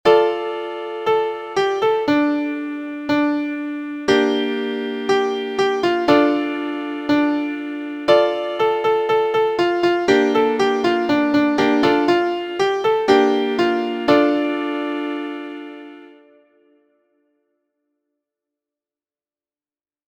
• Origin: Appalachian Folk Song
•  Key: D minor
• Time: 4/4
• Musical Elements: notes: whole, half, quarter, eighth; rests: none; running eighth notes, minor tonality, ascending octave skip on the 6th pitch (La)